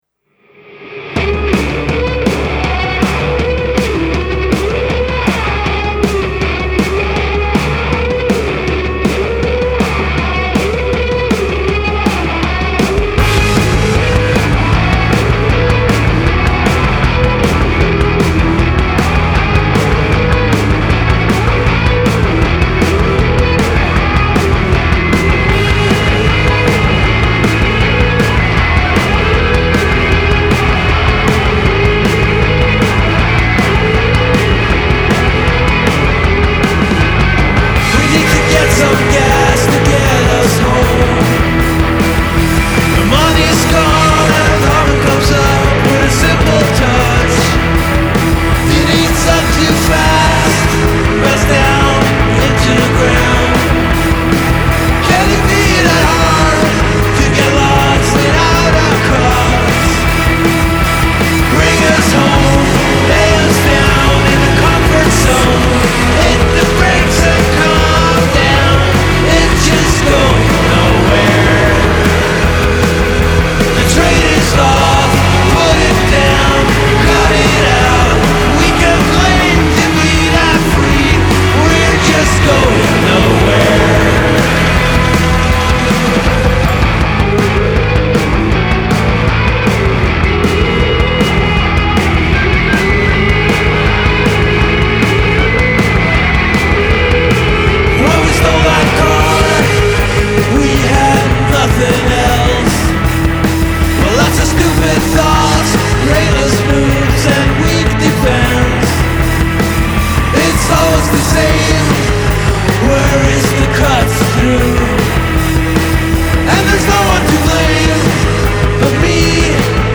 noisy, melodic, & urgent, w/ guitars